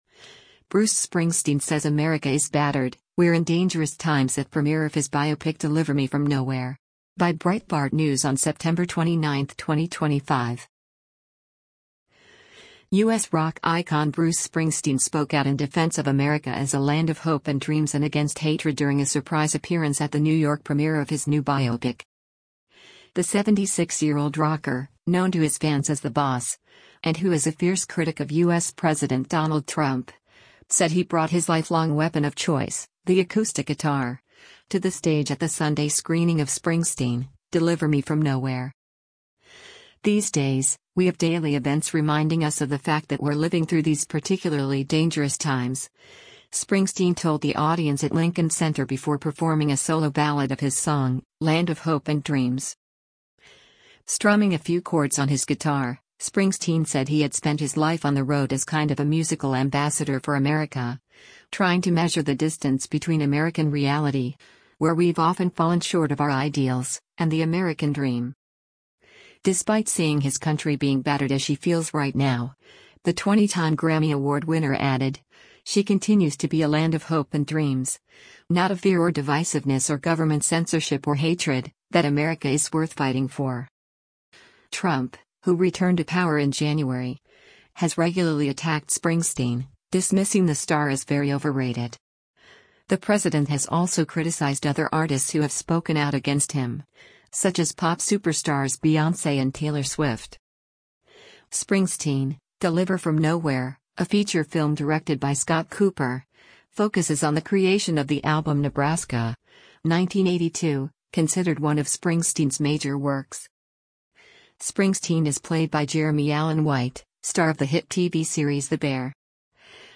” the acoustic guitar